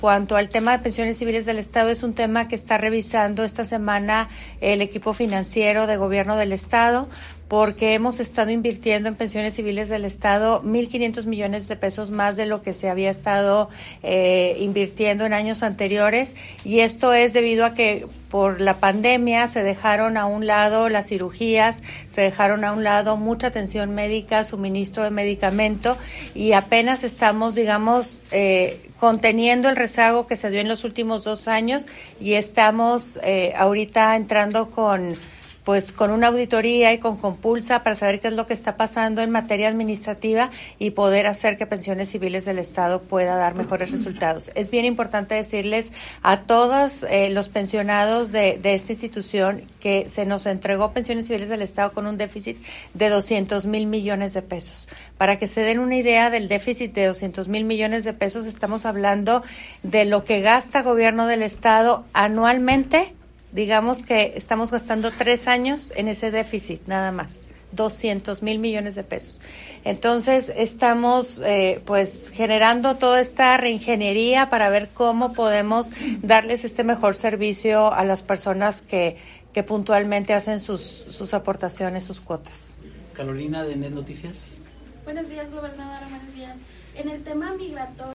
El pasado jueves en Ciudad Juárez, a pregunta de una compañera de la prensa, la gobernadora Maru Campos Galván señalaba que el equipo financiero de Gobierno trabajó esta semana en alternativas para poner orden en PCE, al tiempo que se hacen auditorías financieras y administrativas, denunciando que recibieron el instituto con un déficit de 200 mil millones de pesos y una importante dejación de funciones desde la pandemia de COVID-19.